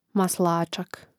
masláčak maslačak